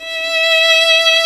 Index of /90_sSampleCDs/Roland L-CD702/VOL-1/STR_Violin 1-3vb/STR_Vln3 _ marc
STR VLN3 E 4.wav